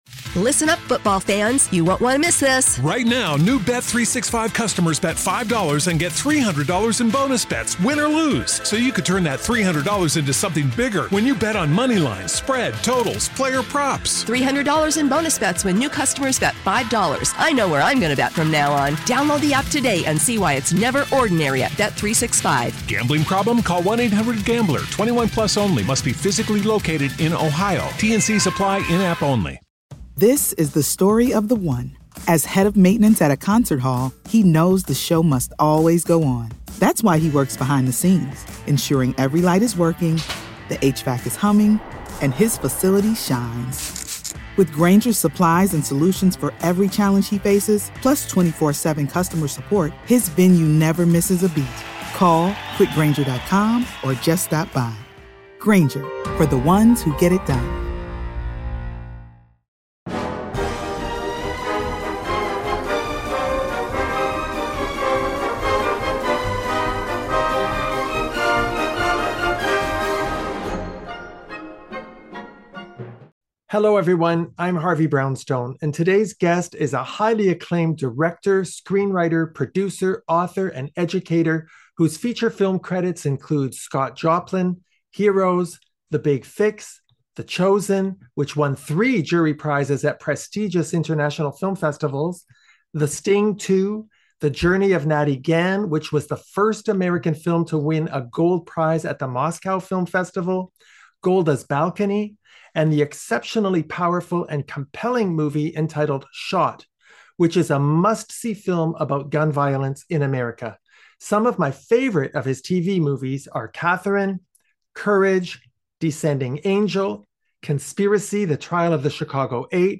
Harvey Brownstone conducts an in-depth interview with Jeremy Kagan, Acclaimed Director, Screenwriter, Producer, Author & Educator